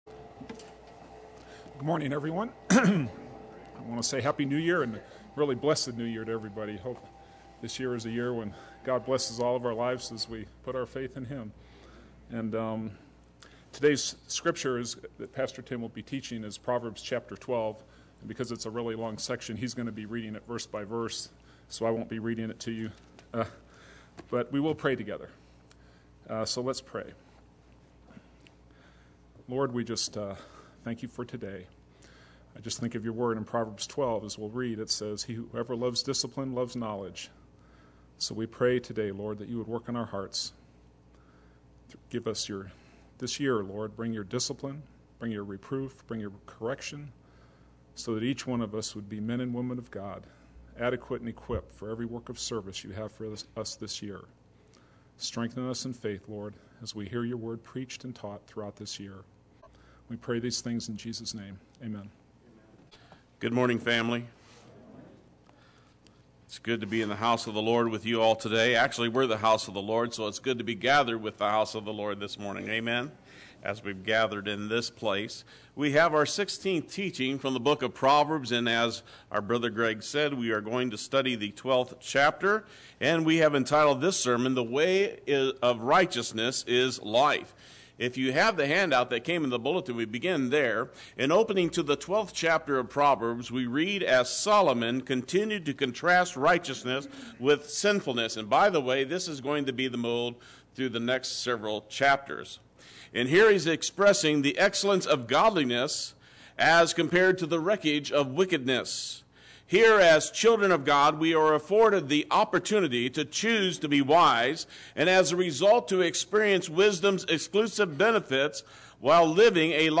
Play Sermon Get HCF Teaching Automatically.
The Way of Righteousness is Life Sunday Worship